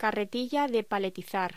Locución: Carretilla de paletizar
voz